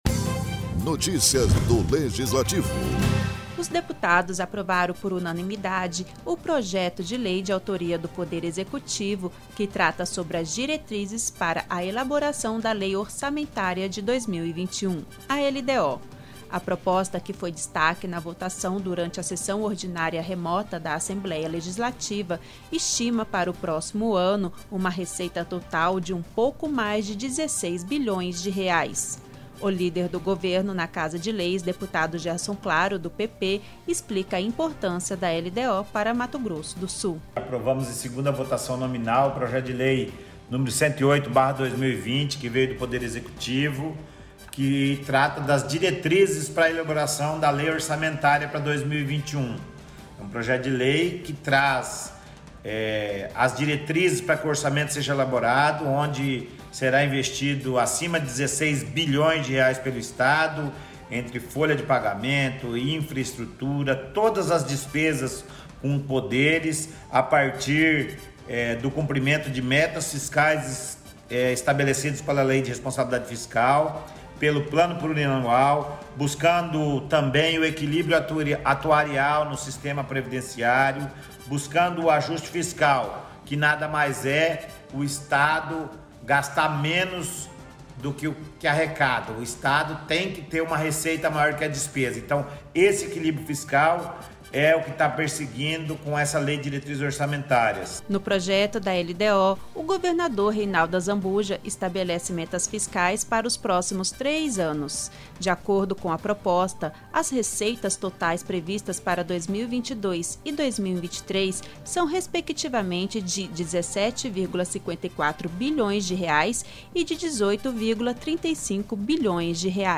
Download Locução e Produção